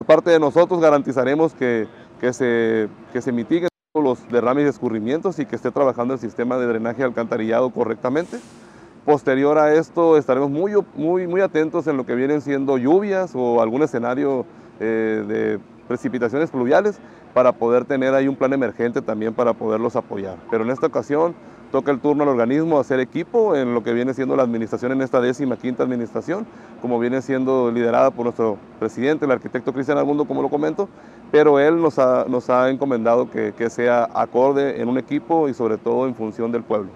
En entrevista con el titular del Organismo Operador, Ramón Rubio Apodaca, mencionó que se está trabajando en corregir algunas zonas heredadas por FONATUR y que actualmente a través FIFONAFE fue entregada a un tercero y que por consecuencia es responsabilidad del OOMSAPAS dar el servicio para darle una solución al tema de las aguas residuales en el Centro y evitar que los habitantes y turistas estén expuestos a focos de infección.